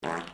sounds / mob / villager / idle2.ogg